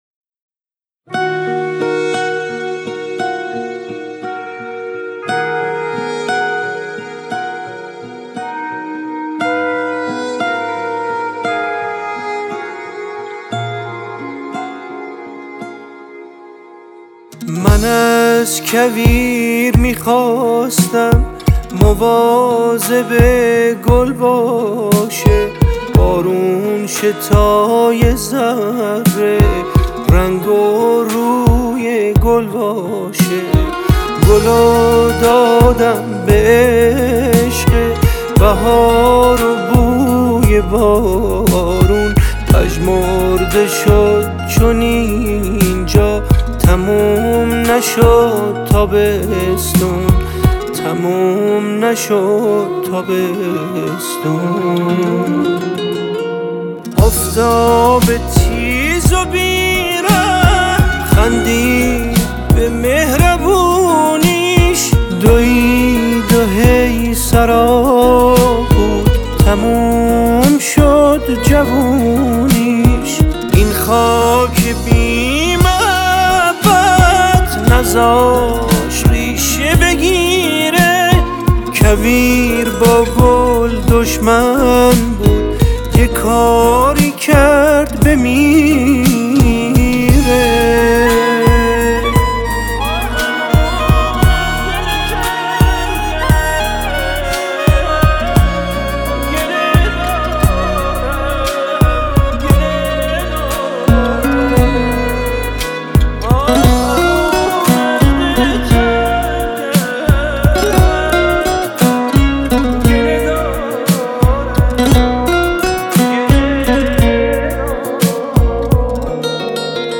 گیتار
ویولن